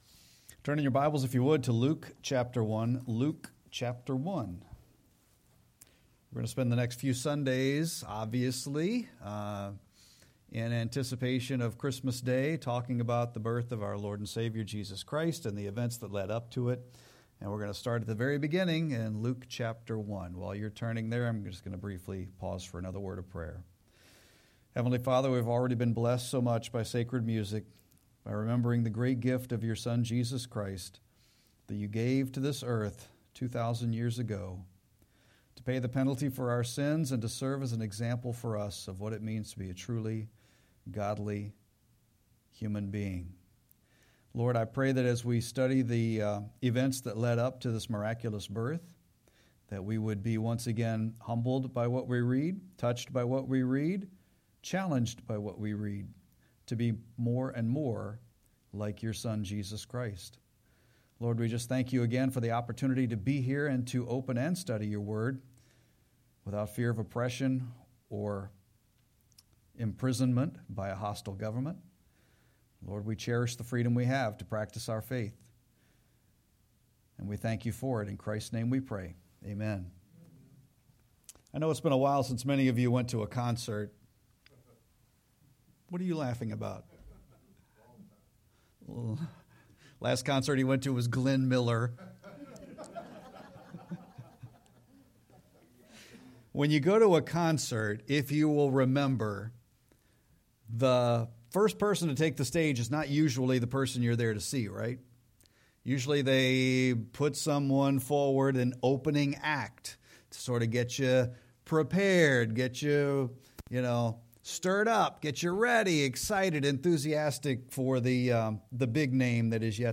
Sermon-12-7-25.mp3